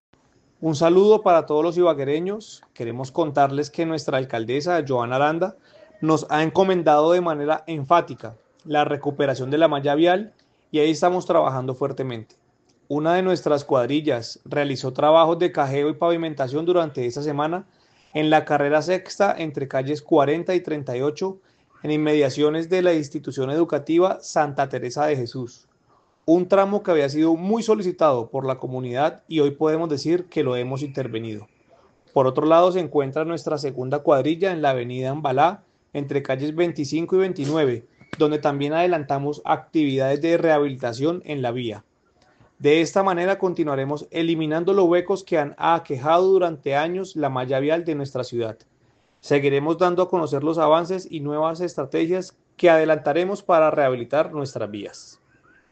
Escuche las declaraciones del secretario de Infraestructura, Matheus Saavedra: